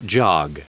Prononciation du mot jog en anglais (fichier audio)
Prononciation du mot : jog